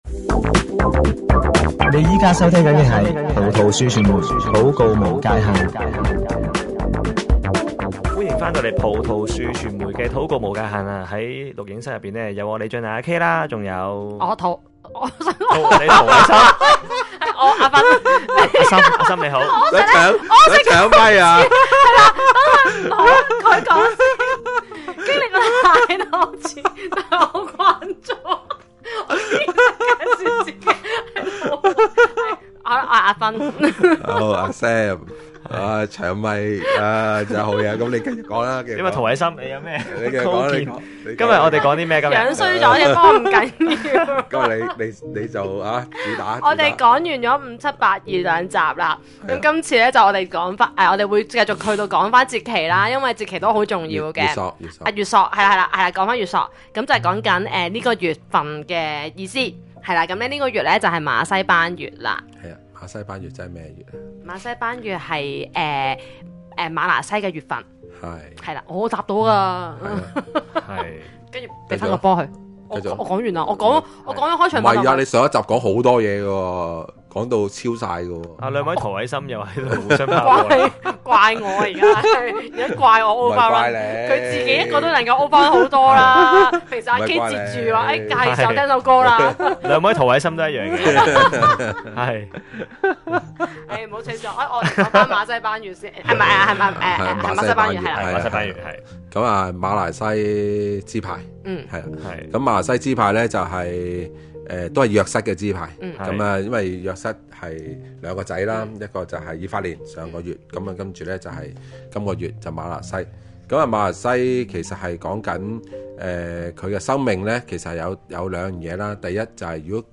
◎節目內播放歌曲◎